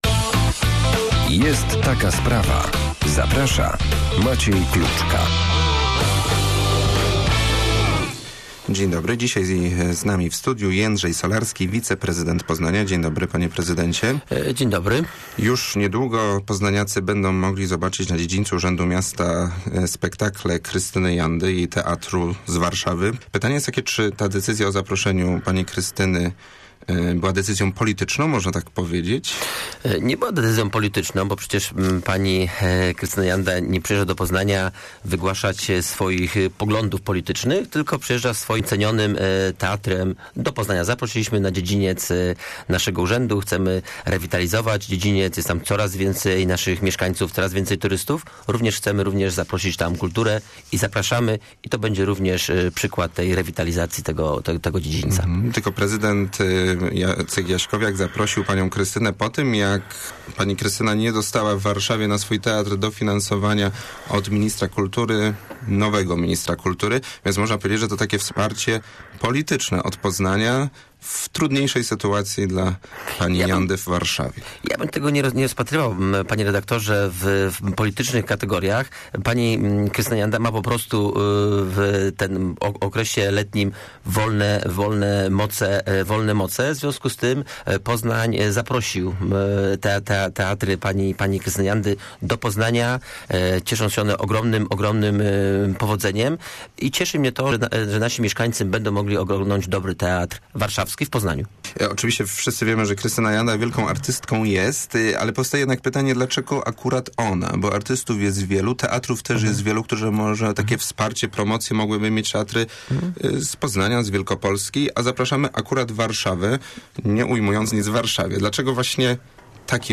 353swcwi03joiyw_rozmowa-z-wiceprezydentem-solarskim.mp3